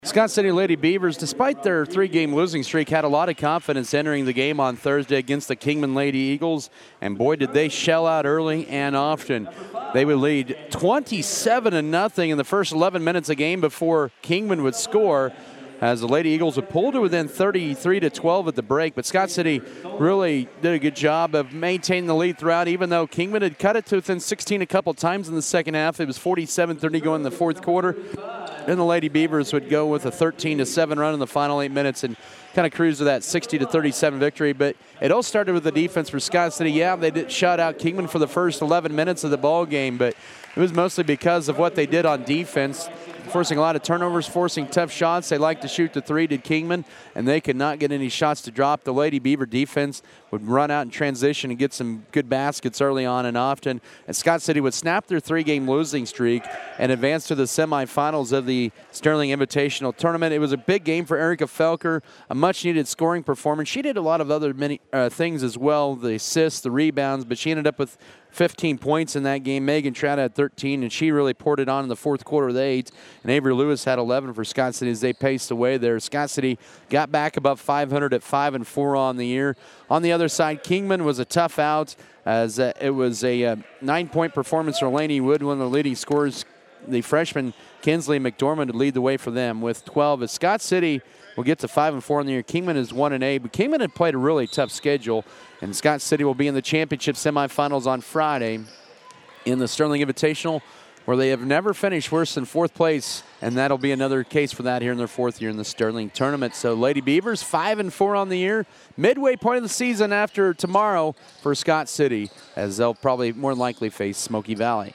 Girls Audio Recap
GIRLS-RECAP-1-18-24.mp3